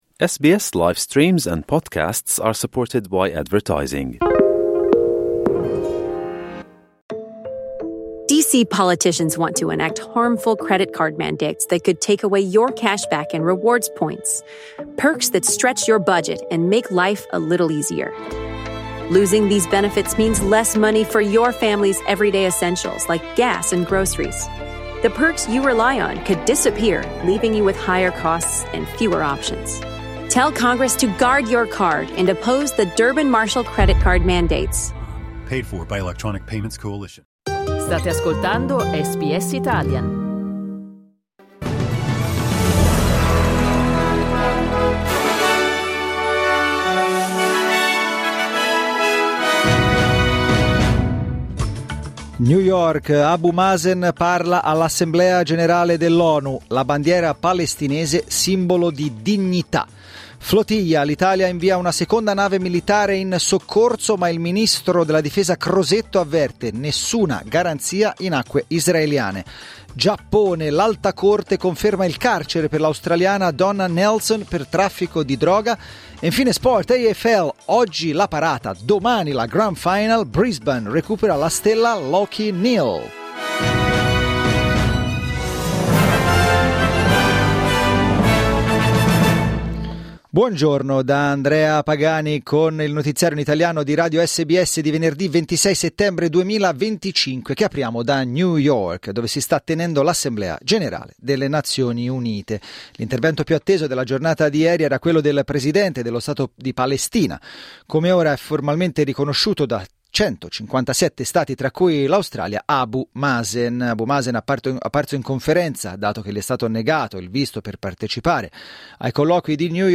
Giornale radio venerdì 26 settembre 2025
Il notiziario di SBS in italiano.